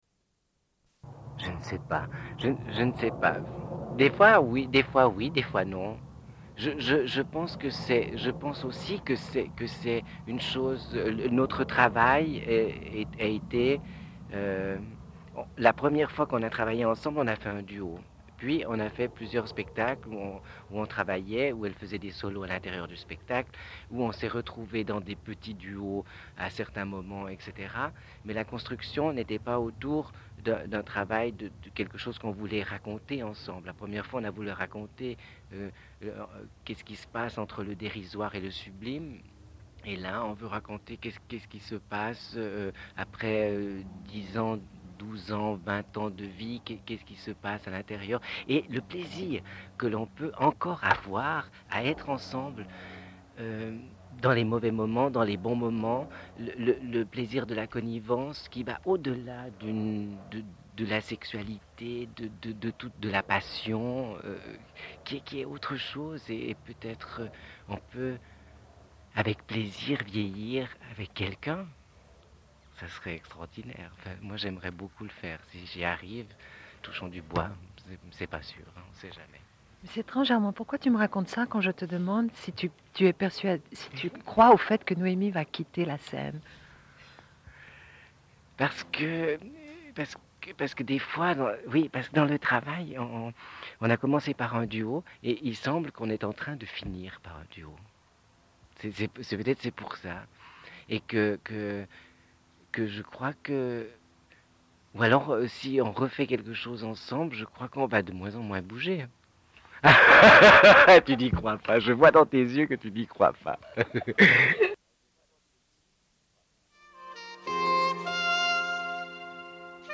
Une cassette audio, face A
Radio